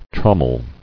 [trom·mel]